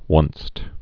(wŭnst)
Southern & South Midland US Once.